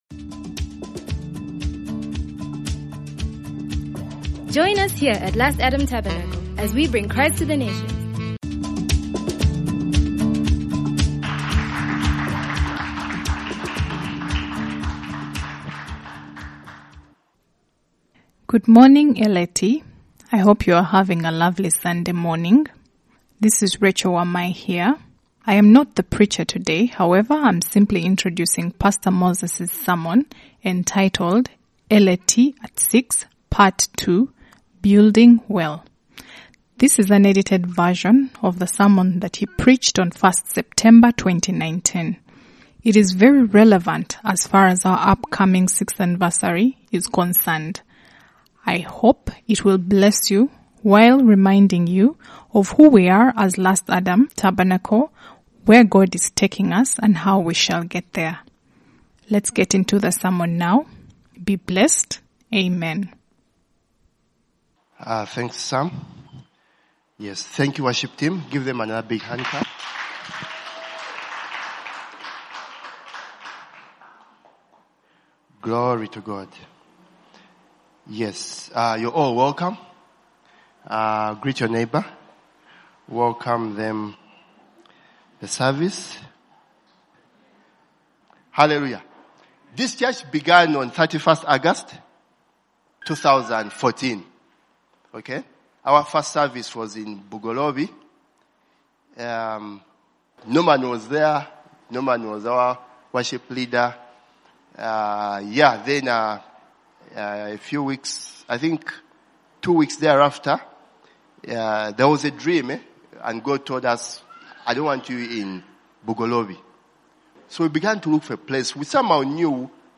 Therefore, this month's sermons will serve the purpose of reminding us why God birthed LAT, how we have successfully reached where we are, and how we shall arrive at and stay in our destiny.